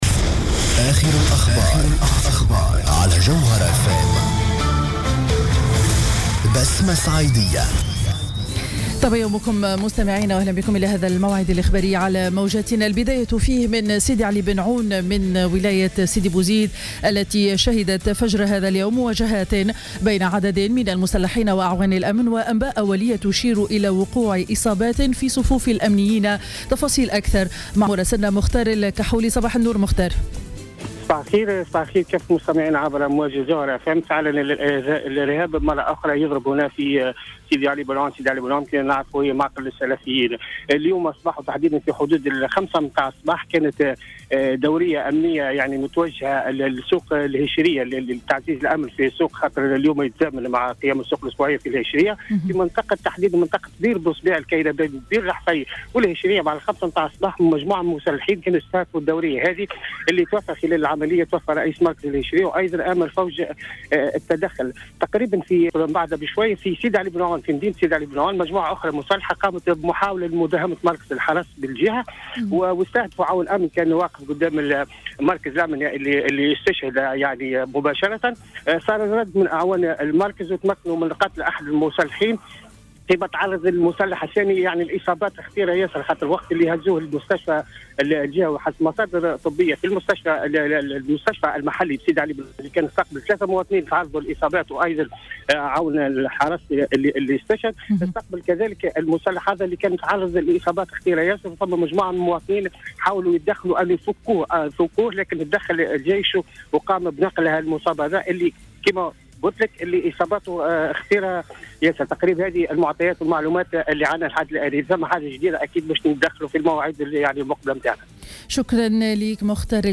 نشرة أخبار السابعة صباحا ليوم الإثنين 15 جوان 2015